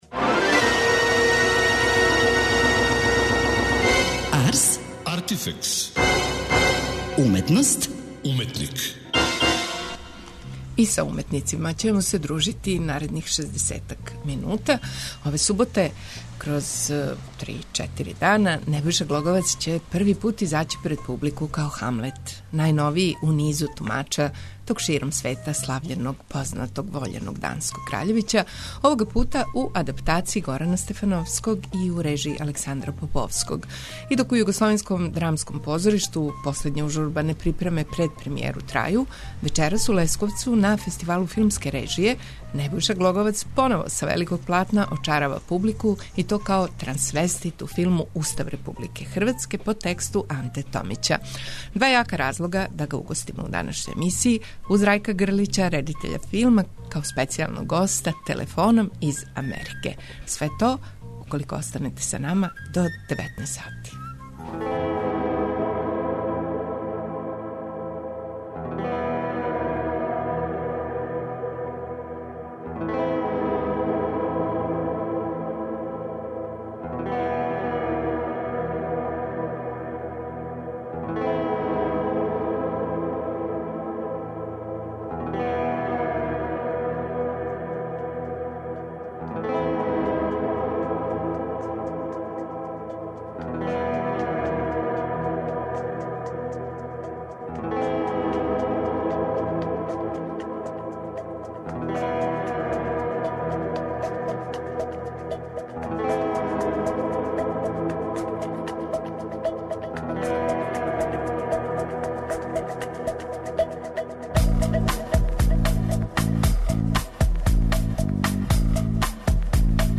Два јака разлога да га угостимо у данашњој емисији, уз Рајка Грлића , редитеља филма, као специјалног госта телефоном из Америке.